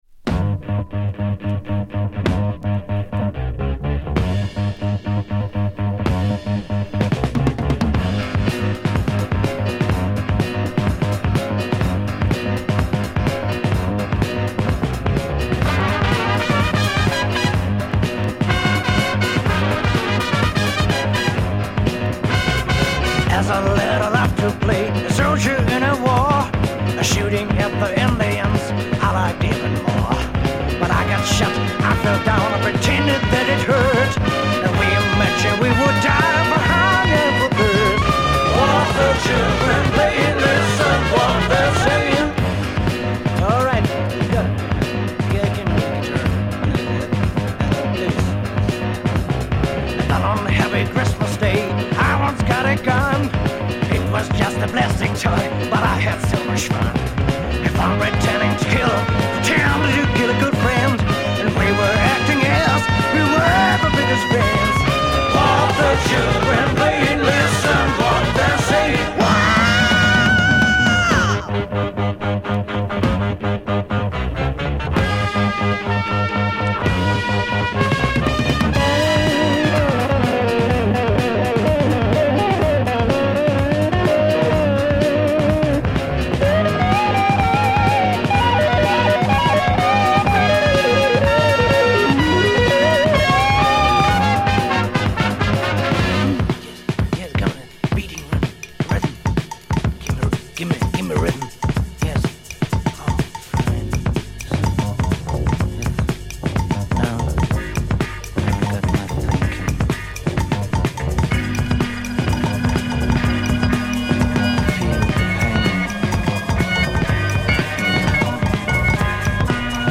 Killer Kraut rock Break hard psych dancer!